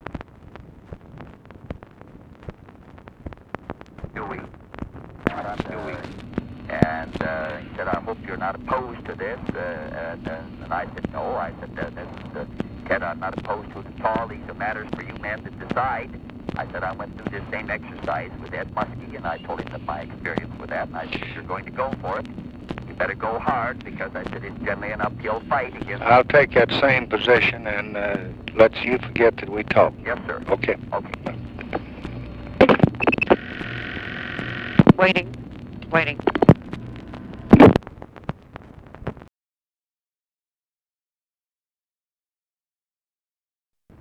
Conversation with HUBERT HUMPHREY, December 31, 1968
Secret White House Tapes